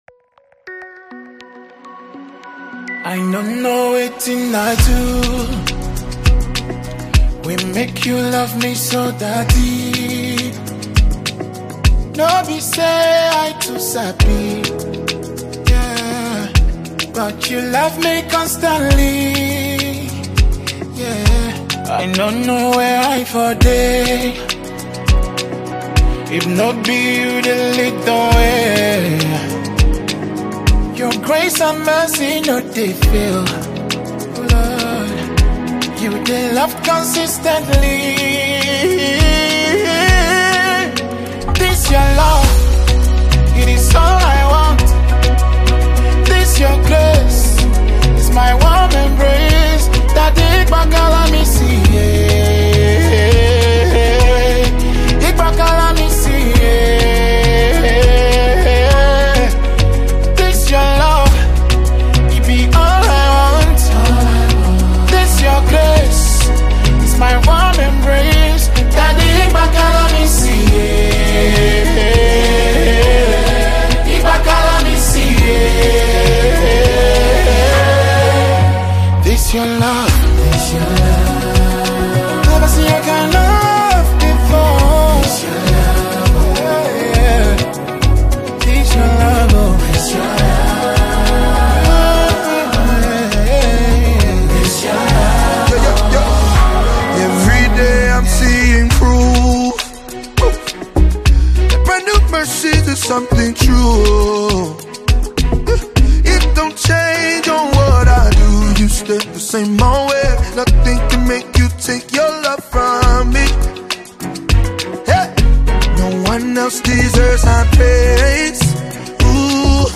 American Gospel Songs Gospel Songs Nigerian Gospel Songs